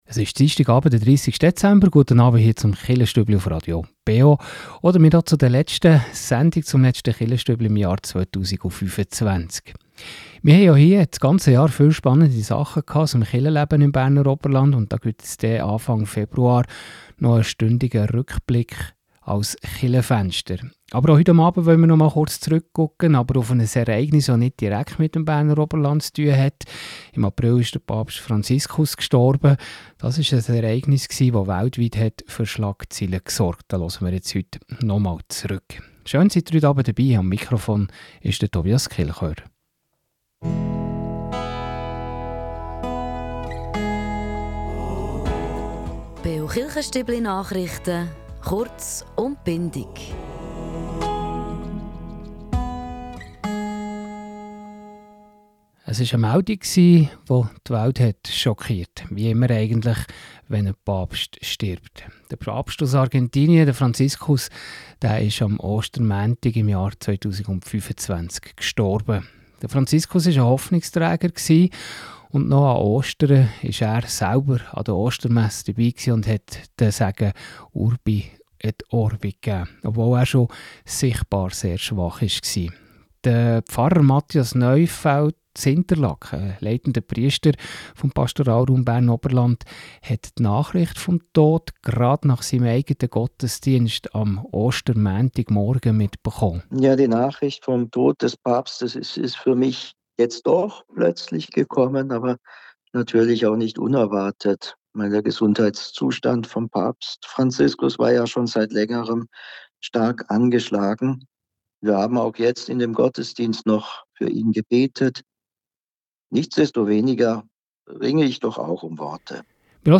In einem Rückblick schauen wir auf den Tod von Papst Franziskus, welches die Christen weltweit beschäftig hat. So hören wir etwas Kardinal Kurt Koch, der über die Spiritualität von Franziskus und über die Freiheiten, welche er seinem Umfeld gelassen habe, spricht.